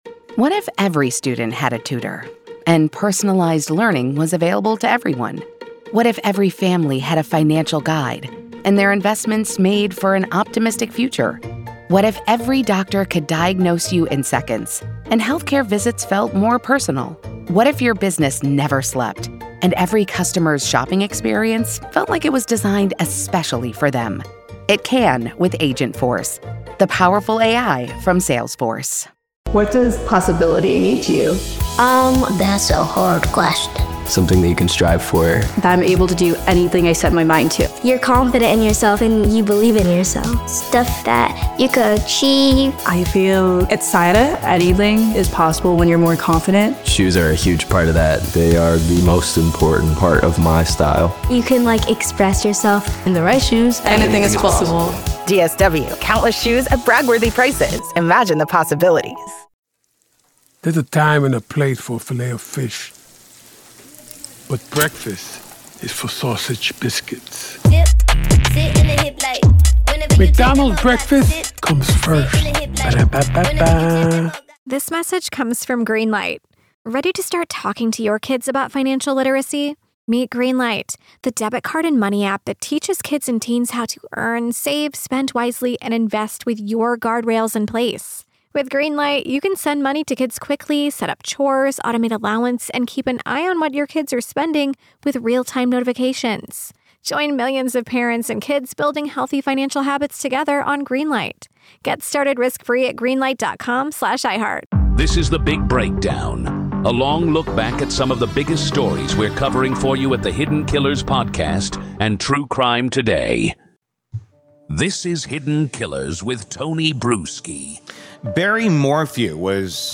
You’ll hear from legal analysts and investigators as we break down each piece of the puzzle and how the prosecution plans to tell this story to a jury.